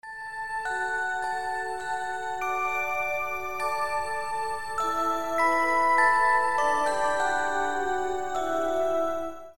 без слов
рождественские